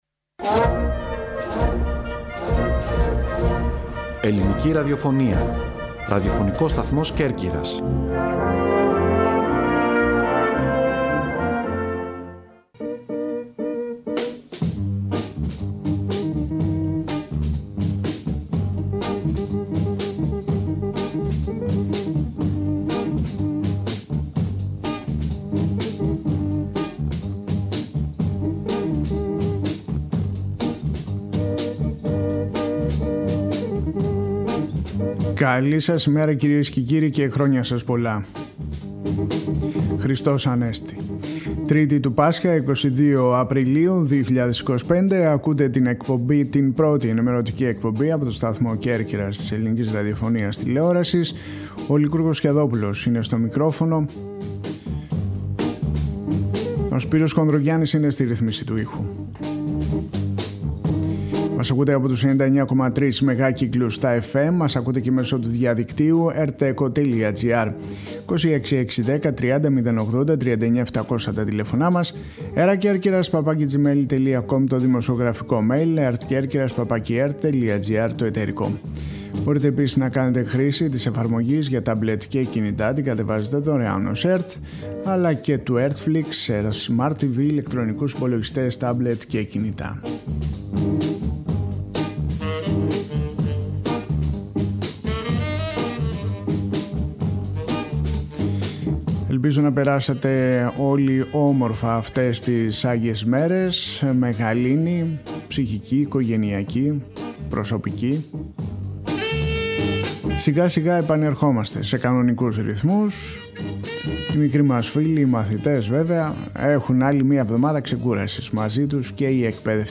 «Εννέα στην ΕΡΤ» Οριοθέτηση της ειδησιογραφίας στην Κέρκυρα, την Ελλάδα και τον κόσμο, με συνεντεύξεις, ανταποκρίσεις και ρεπορτάζ.